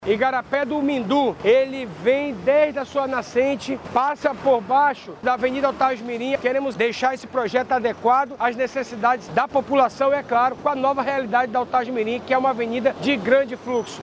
O secretário da Seminf, Renato Junior, explica que a pasta fará algumas adequações no projeto, visando além da infraestrutura do local, a melhoria no trânsito.